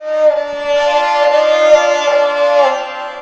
SARANGHI1 -R.wav